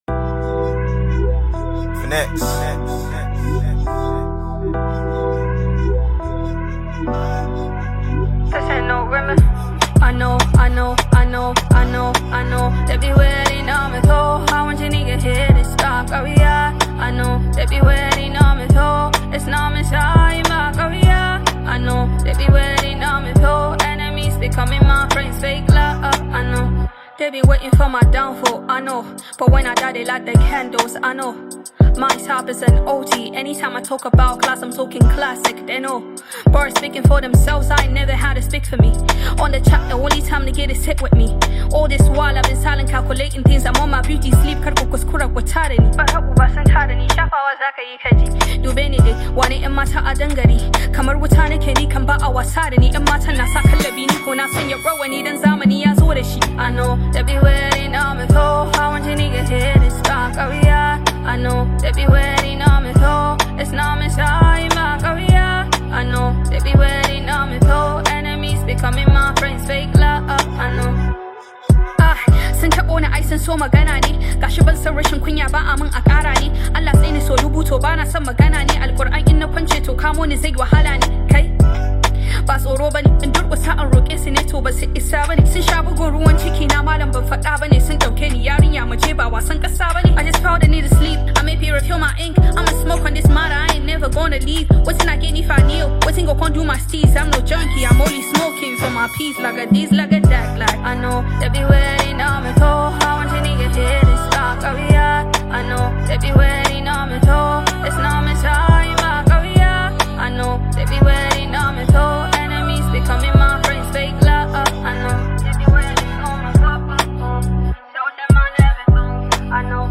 Hausa Hiphop